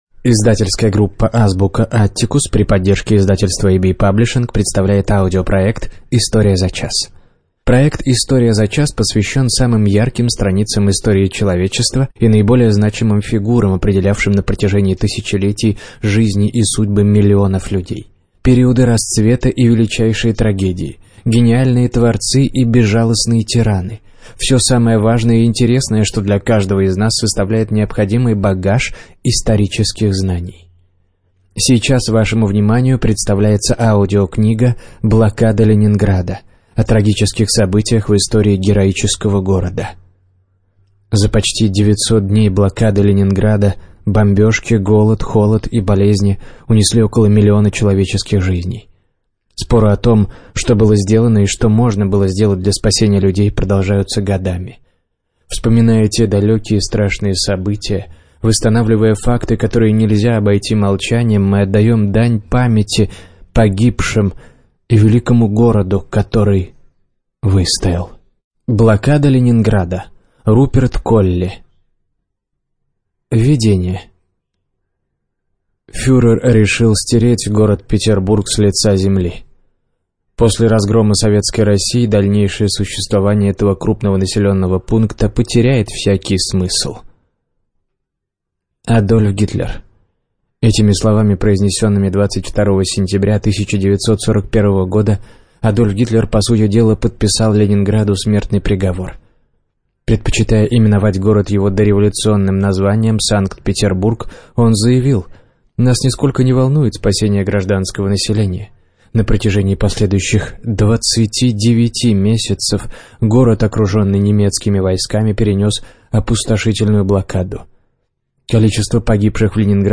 Студия звукозаписиAB-Паблишинг